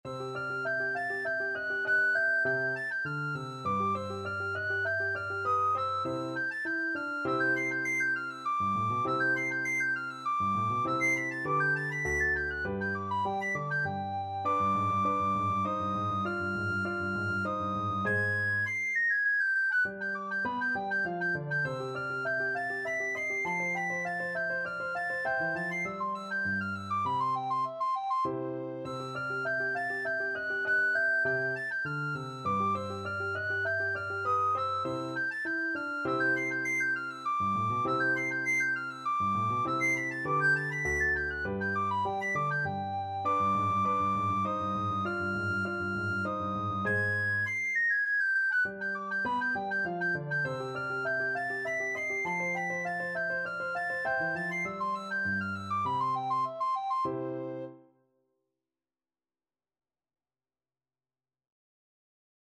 Free Sheet music for Soprano (Descant) Recorder
3/4 (View more 3/4 Music)
Classical (View more Classical Recorder Music)